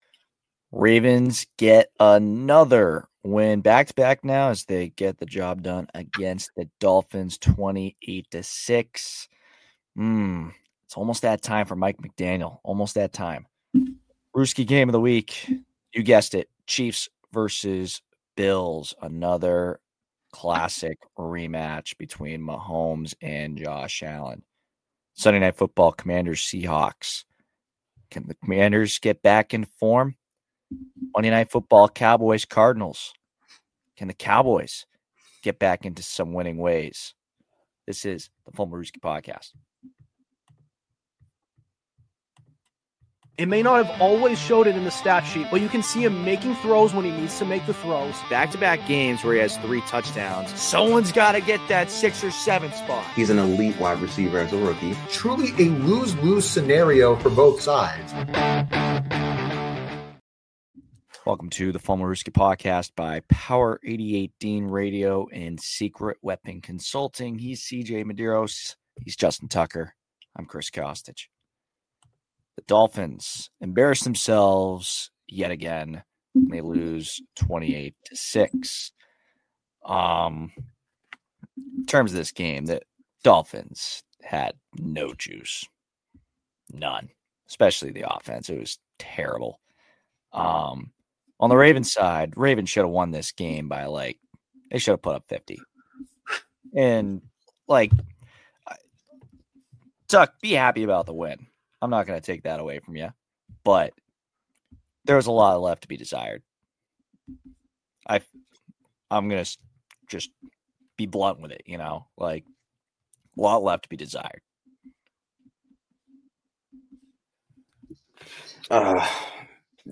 An NFL podcast where we discuss all things pro-football-related, including recent news and hot takes. Hosted by four college guys